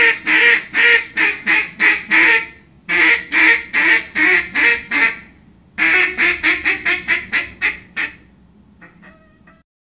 Звуки уток
Одинокое кряканье утки